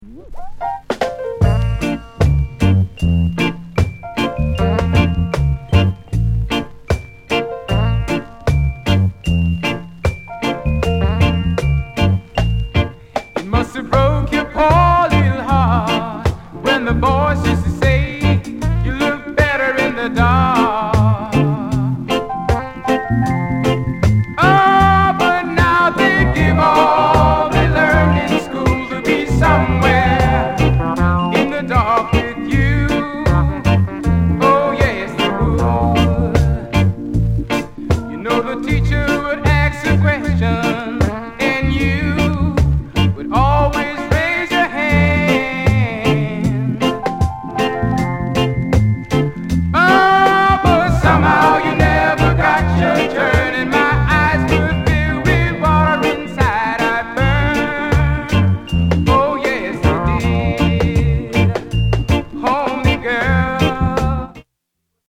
SOUL COVER